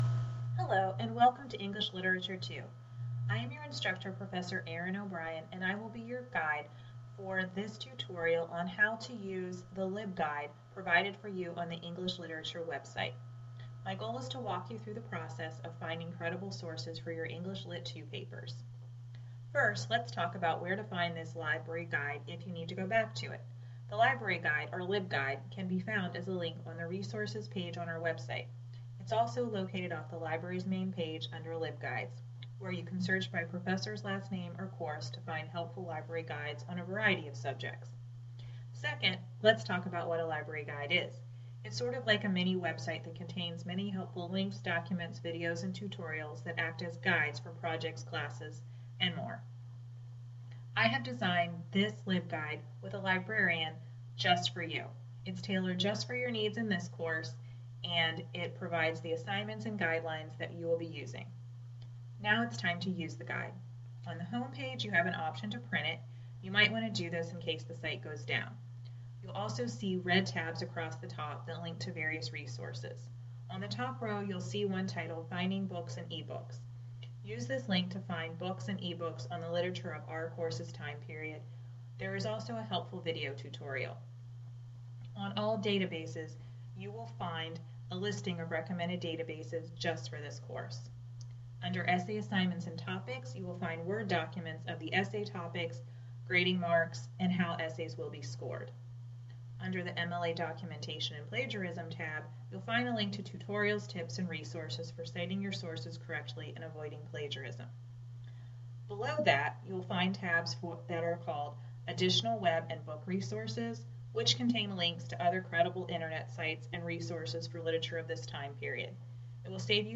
Podcast: Here is a narrated tutorial on how to use the library guide mentioned above.